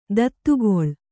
♪ dattugoḷ